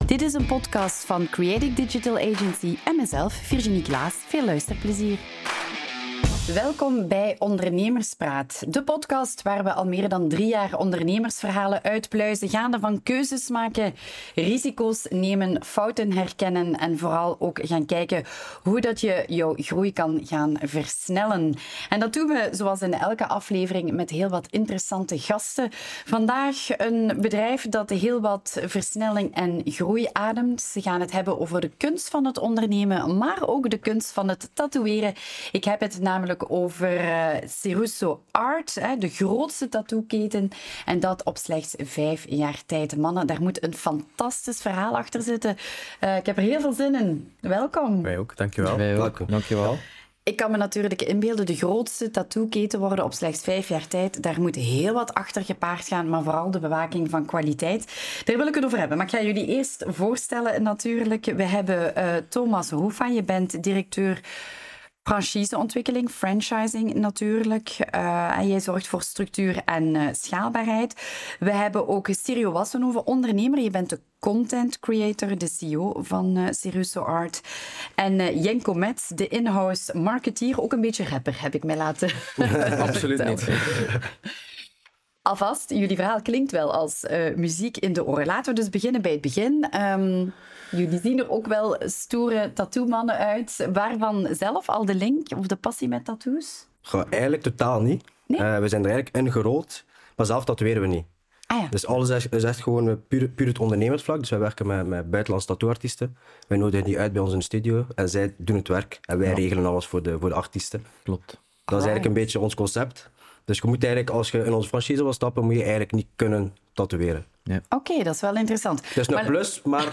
Audio narration of: How Cirusso Art Became a Tattoo Empire in 5 Years